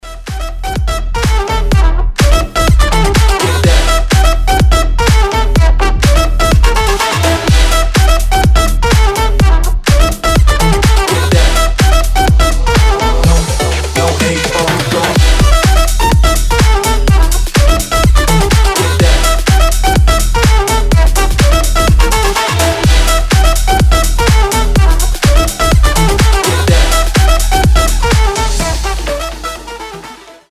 • Качество: 320, Stereo
громкие
мощные
EDM
Саксофон
Стиль: future house